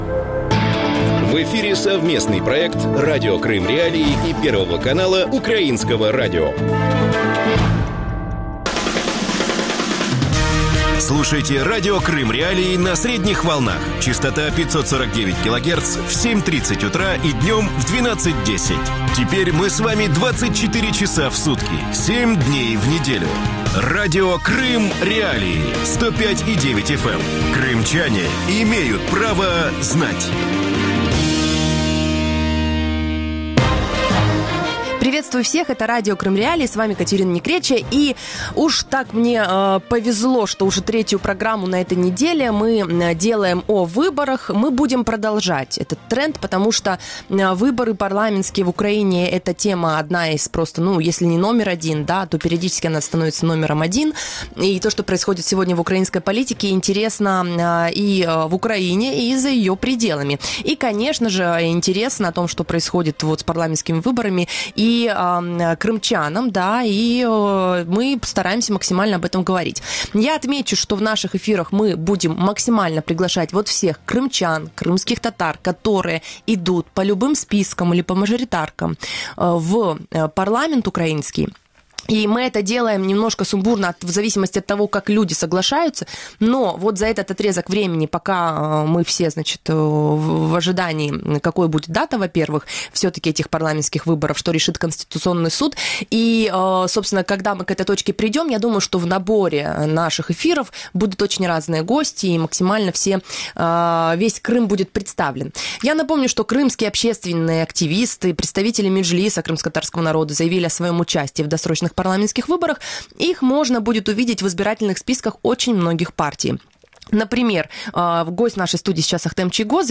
Какой видит украинскую политику в отношении Крыма Ахтем Чийгоз? Что может сделать народный депутат Украины для крымчан на полуострове? Почему Чийгоз выбрал партию «Европейская солидарность»? Гость эфира: Ахтем Чийгоз, заместитель главы Меджлиса крымскотатарского народа.